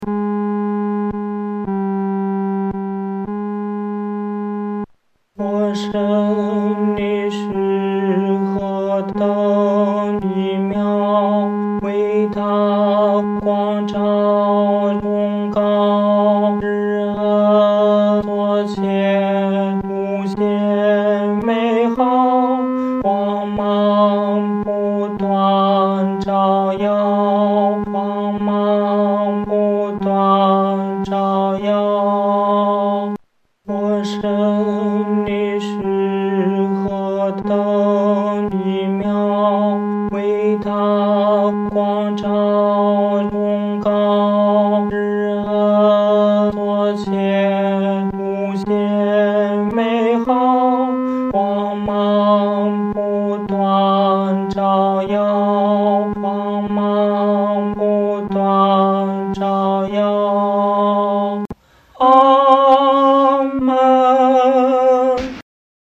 男高